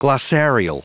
Prononciation du mot glossarial en anglais (fichier audio)
Prononciation du mot : glossarial